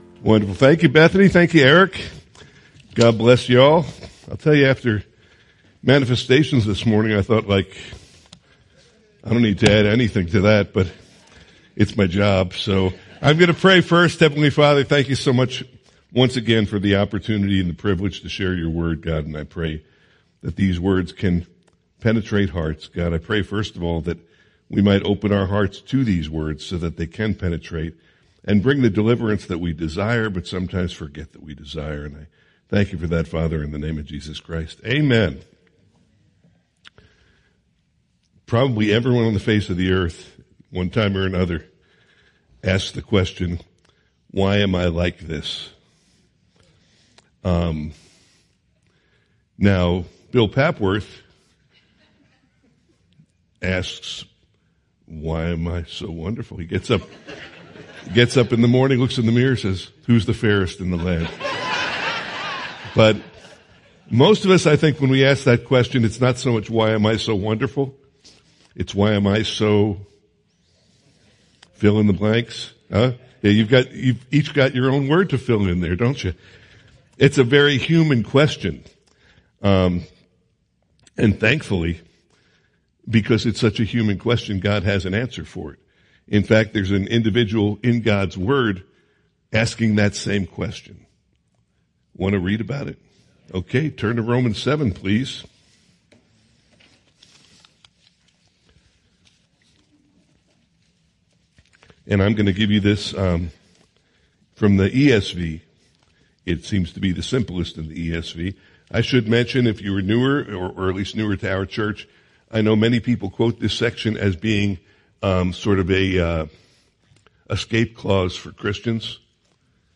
Teachings | Teachings